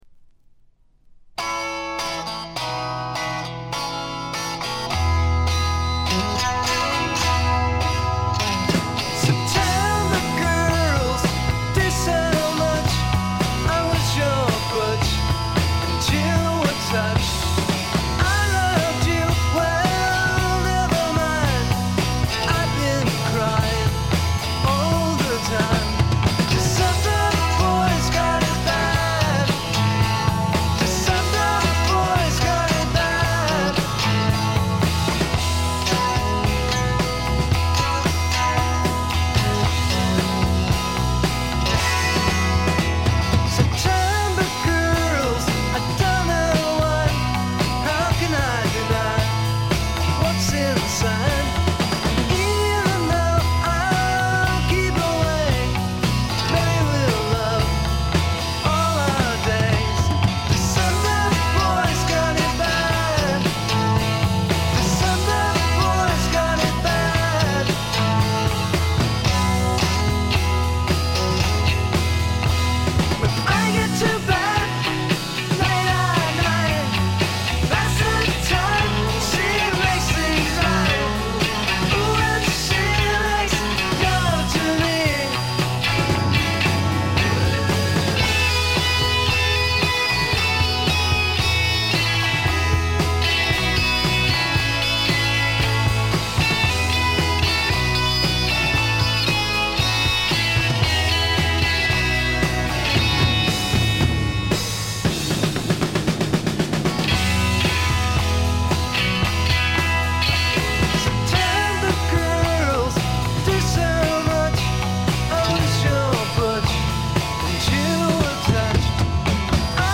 散発的なプツ音が3ヶ所ほど（ほとんど気付かないレベル）。
試聴曲は現品からの取り込み音源です。